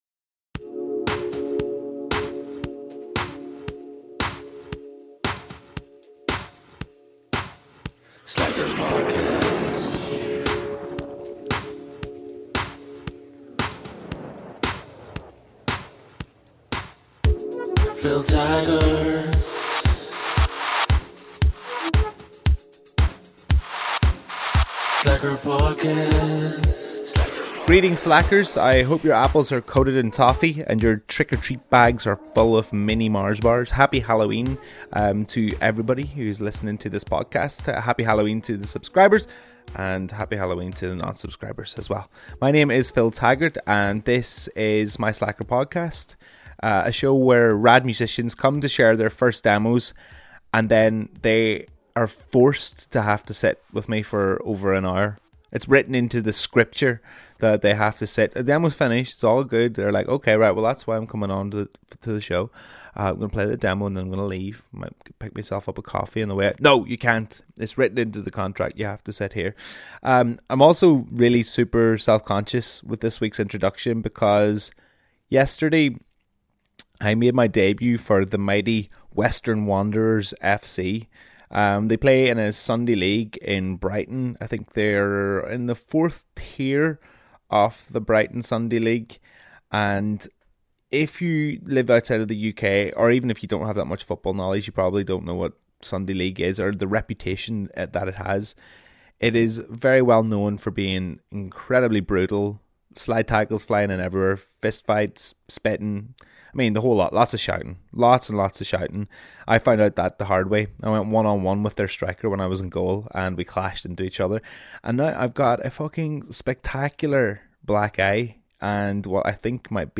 This week's guest is Kate Nash.